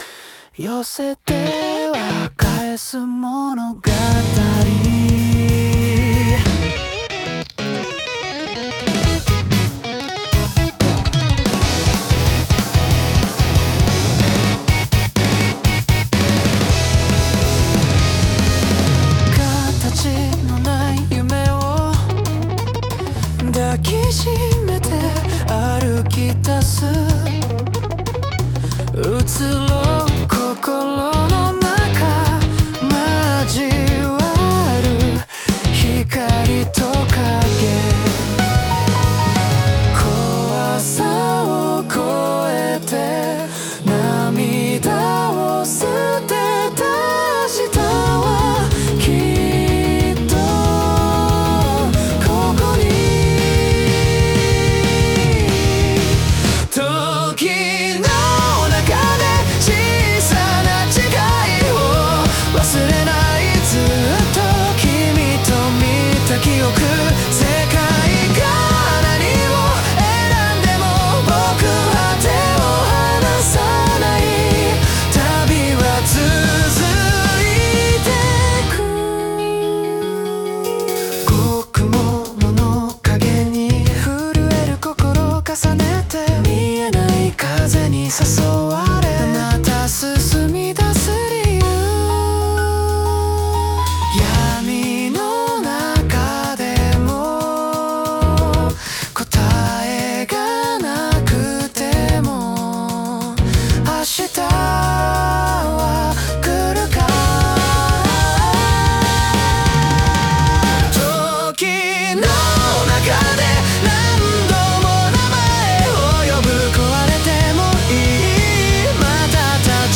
男性ボーカル
イメージ：J-ROCK,男性ボーカル,かっこいい,切ない